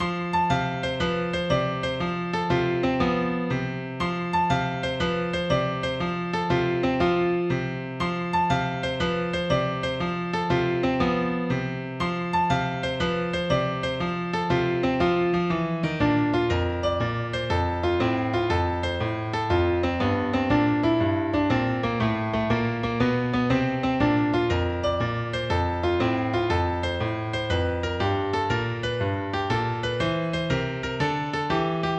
ワクワクを軽快なピアノで表現しました
無限ループピアノほのぼの